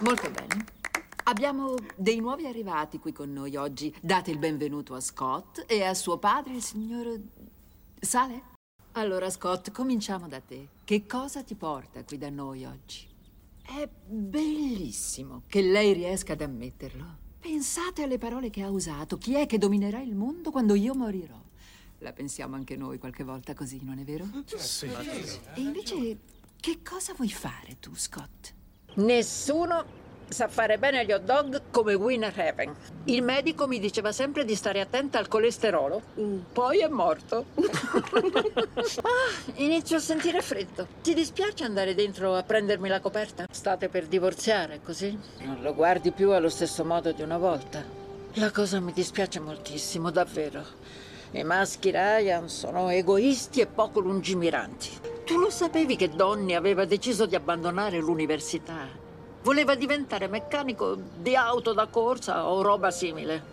Il mondo dei doppiatori